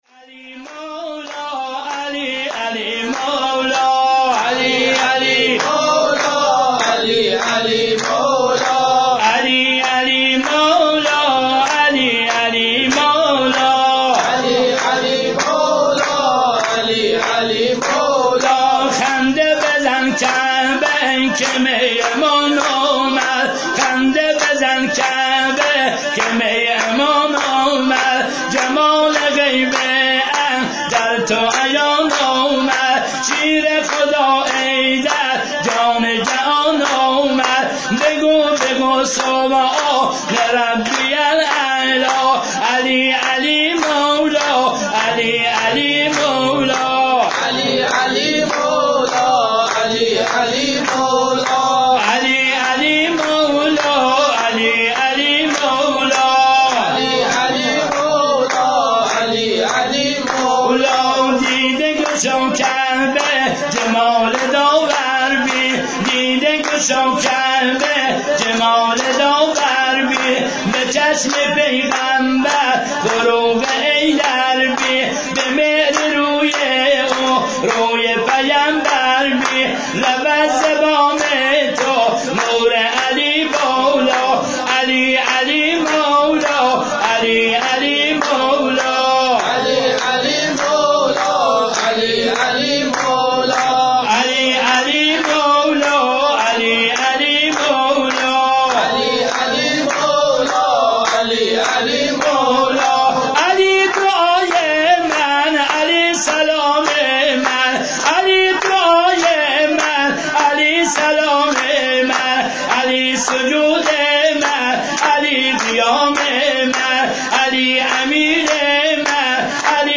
ولادت امام علی{ع}96